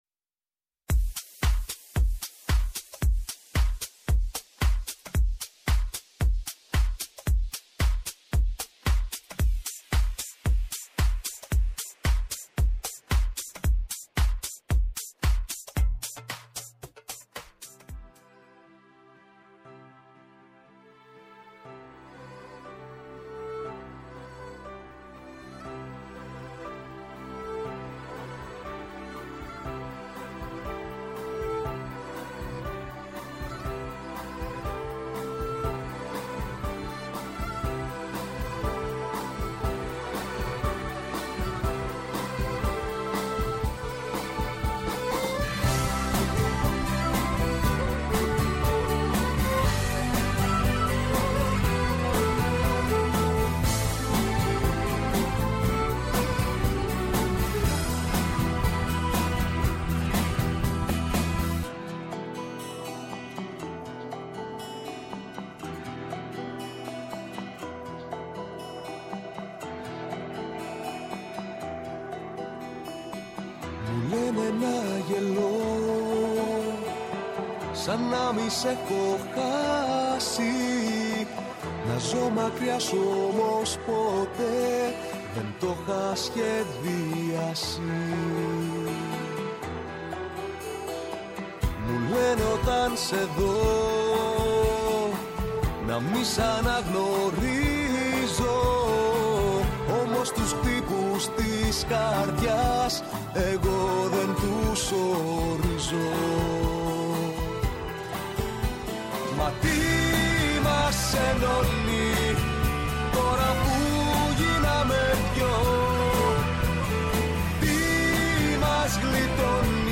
Καλεσμένος στο στούντιο ο τραγουδοποιός